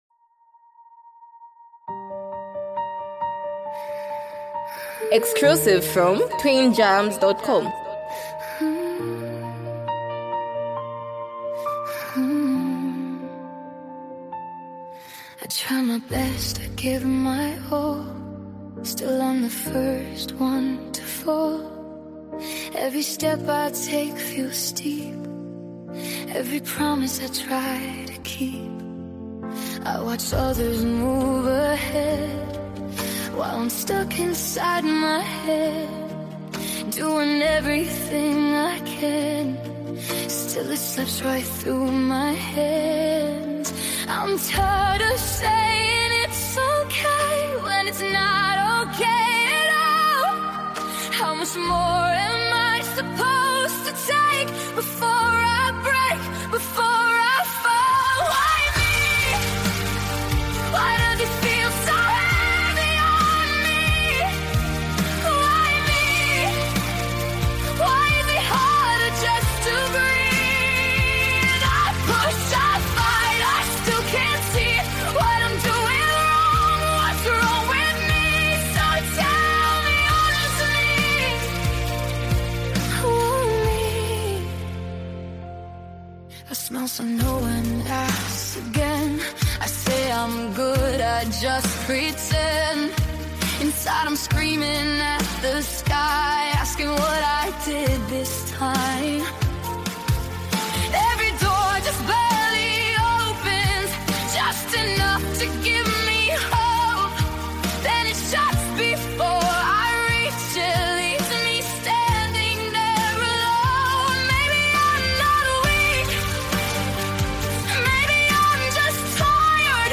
an emotional and reflective song